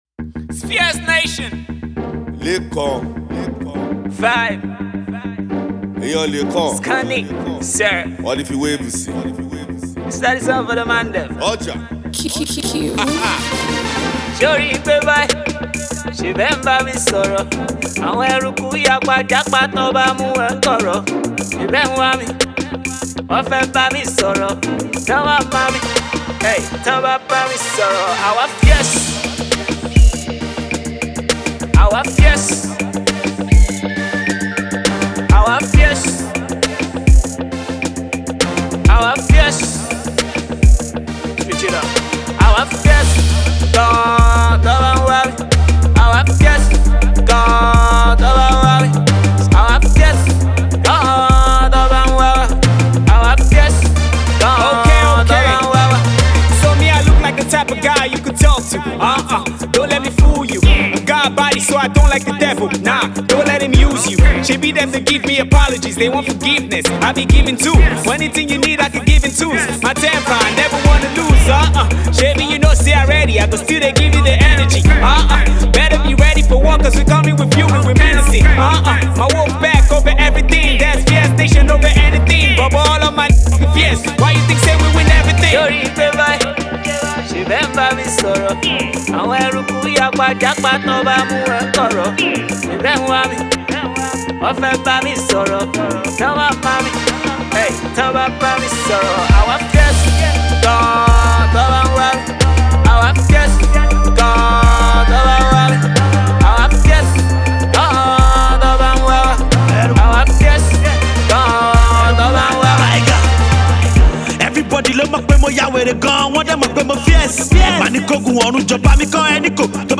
Afrobeat/Hiphop